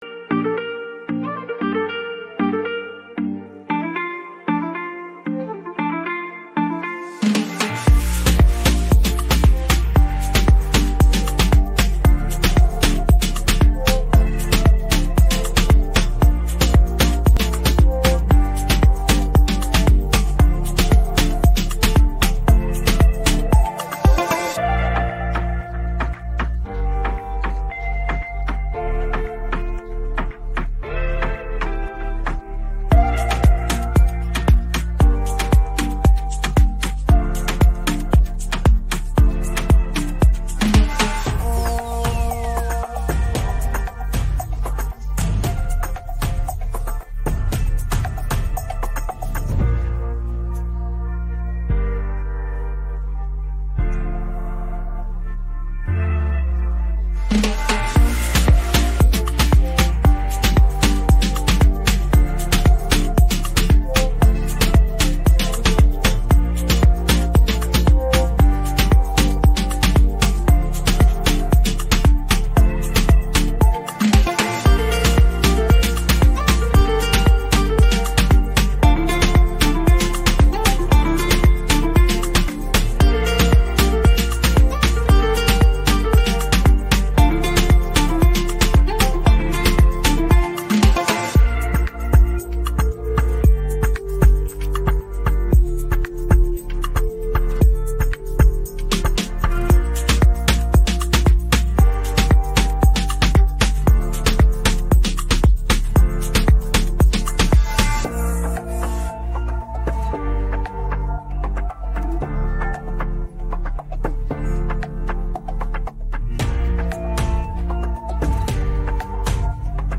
українське караоке 622
Українські хіти караоке